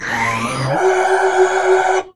主机的声音" 嗯
标签： 维吉尔 语音 船舶 SCI 错误 失真 计算机 机器人 人工 柯塔娜 主机 智能 人工智能 空间 科幻 HAL 网络连接
声道立体声